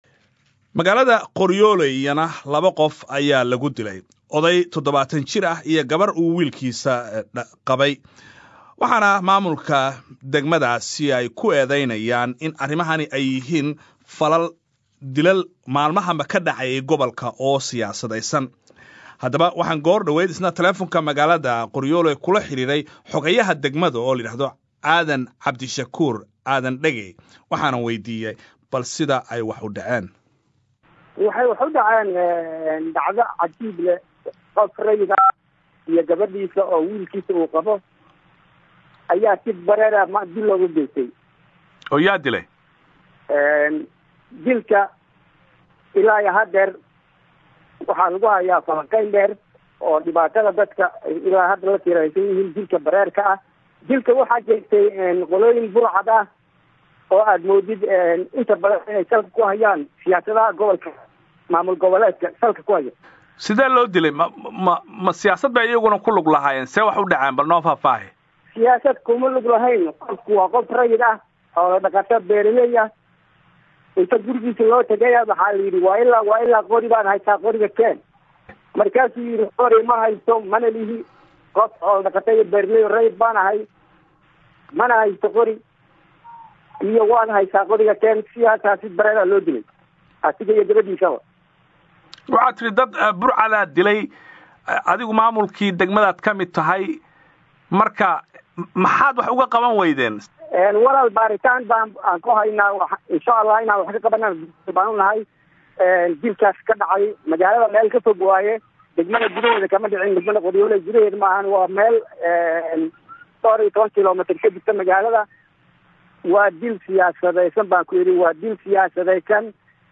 Qoryooley(INO)-Wareysi BBC-da ay la yeelatay nin isku sheegay inuu yahay Xoghayaha degmada Qoryooley ee gobolka Sh/hoose ayaa eedeyn loogu soo jeediyey maamulka cusub ee Koonfur Galbeed inuu ka dambeeyo dil la sheegay in maanta odey iyo gabar loogu geystay degmada Qoryooley.